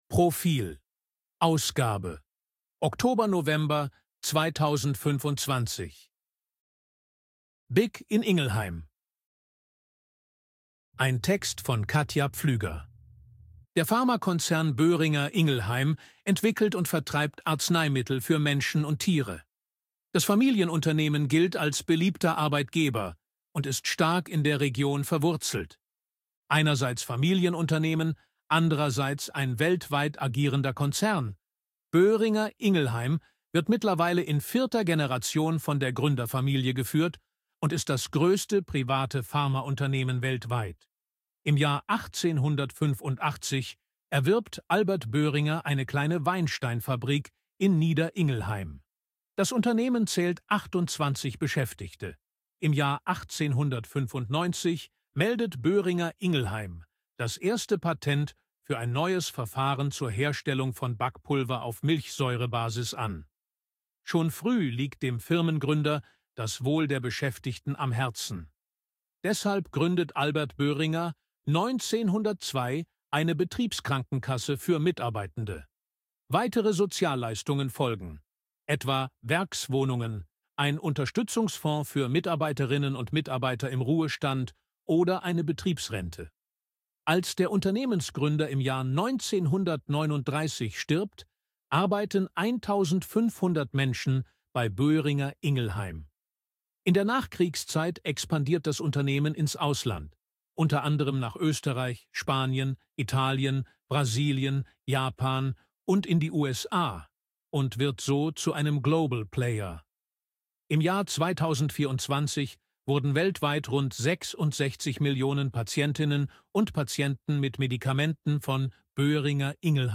ElevenLabs_255_KI_Stimme_Mann_AG-Check.ogg